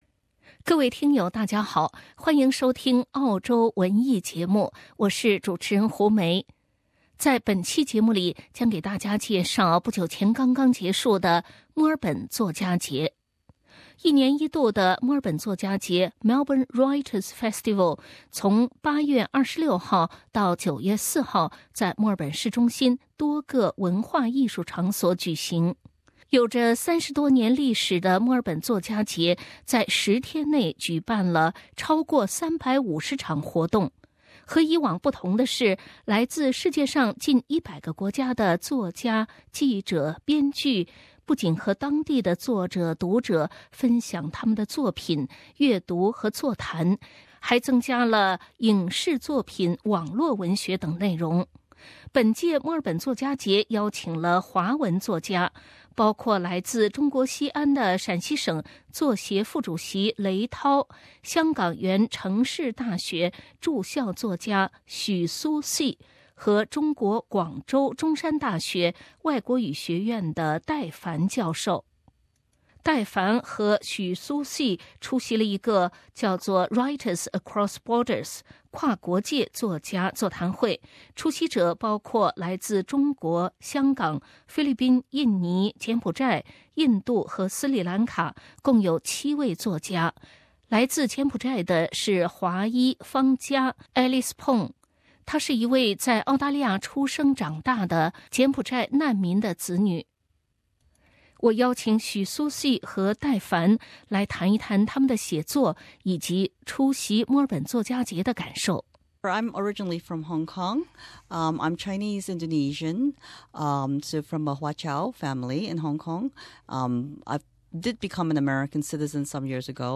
澳洲文艺 - 墨尔本作家节访谈录